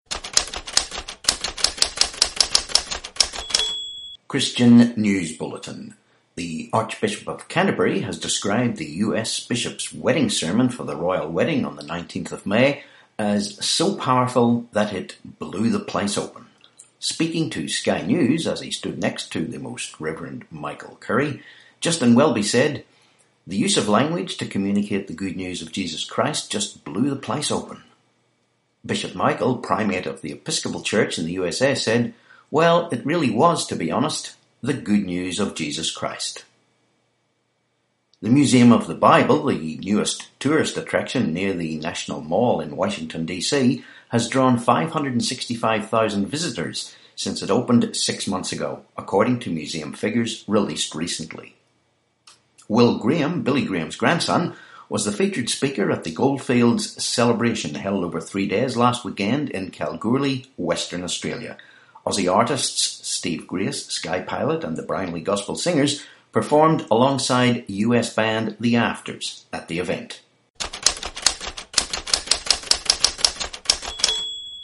27May18 Christian News Bulletin